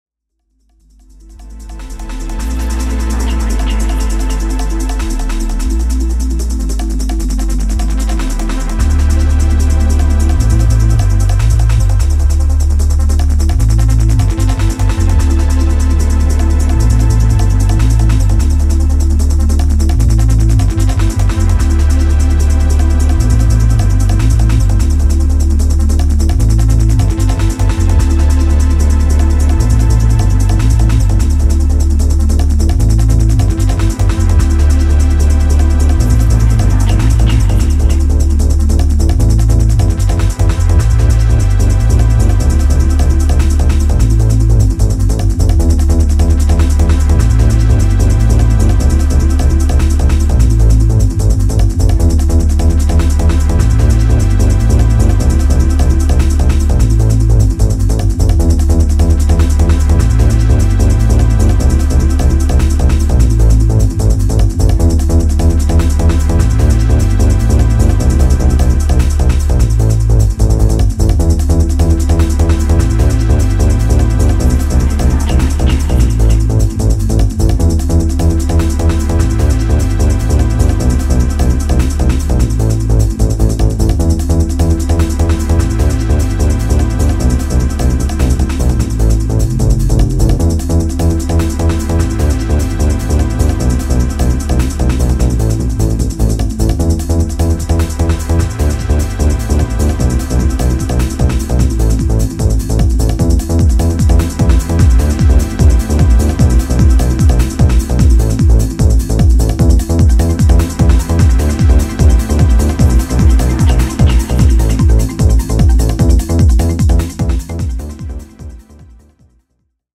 Techno Acid Breaks Rave